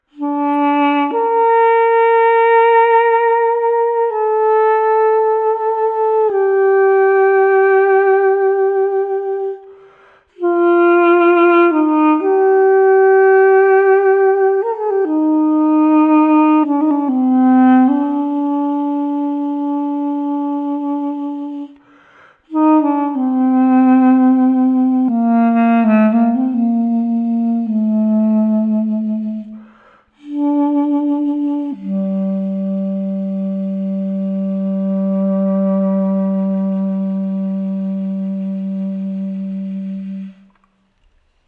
Человек исполняет мелодию на дудуке